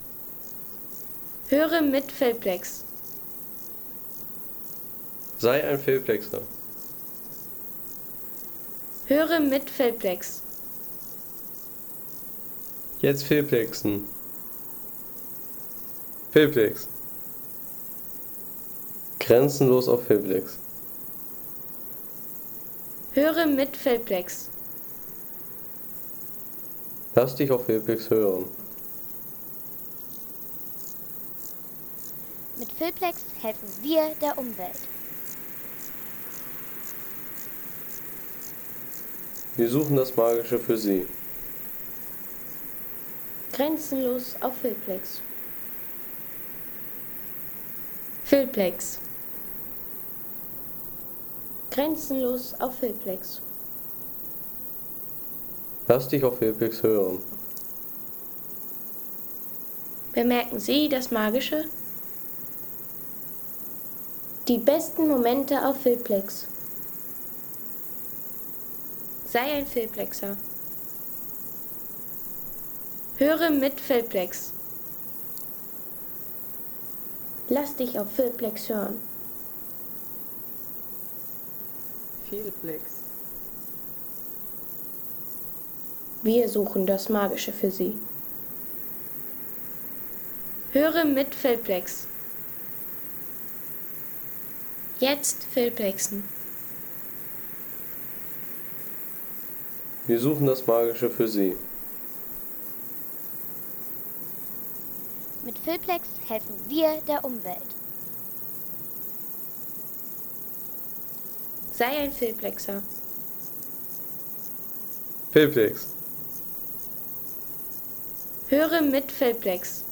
Idyllische Klänge aus Ötztal-Mitte – Naturgenuss auf 1539 Metern.